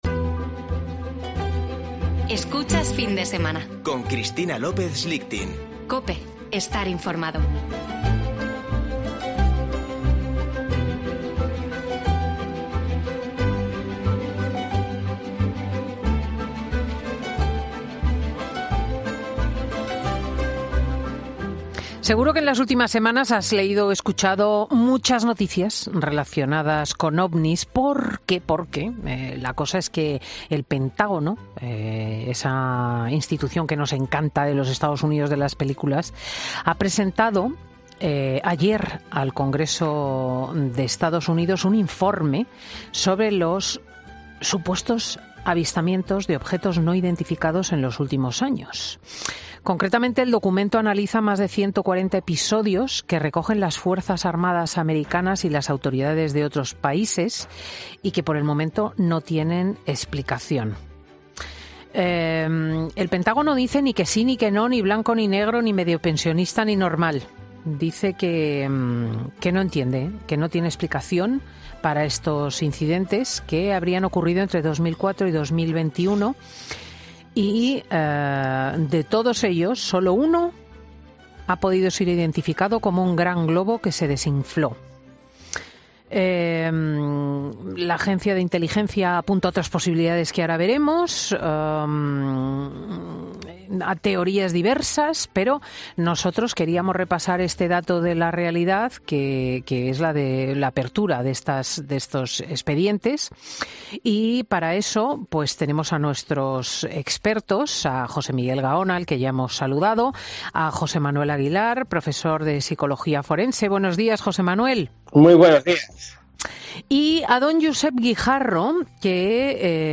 Tertulia de chicos: ¿estamos más cerca de encontrarnos con los OVNIS?